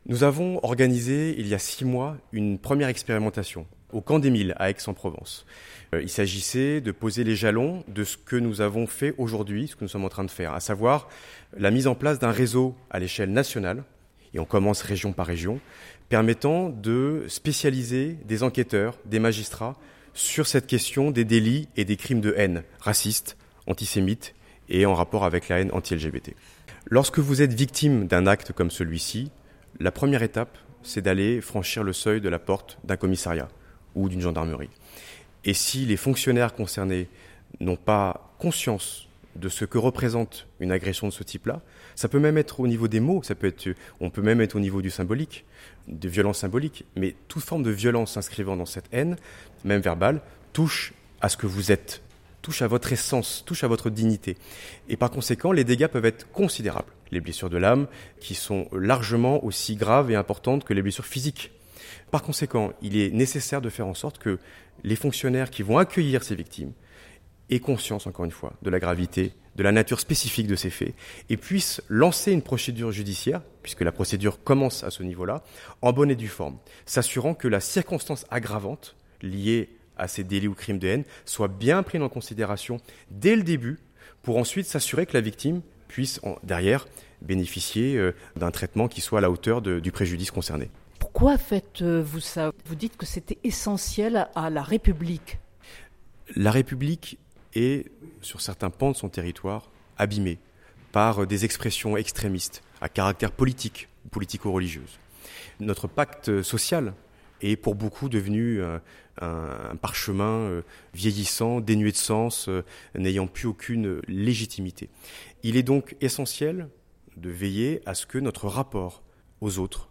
Une journée de formation -organisée par la Dilcrah et co-pilotée avec les ministères de l’Intérieur et de la Justice, en partenariat avec le Camp des Milles- s’est tenue le 1er juillet en Préfecture de région Provence-Alpes-Côte d’Azur à Marseille.
son_copie_petit-368.jpgChristian Gravel, préfet et conseiller aux affaires de sécurité à la Dilcrah (Délégation interministérielle à la lutte contre le racisme, l’antisémitisme et la haine anti-LGBT) revient sur les raisons et l’importance de cette formation…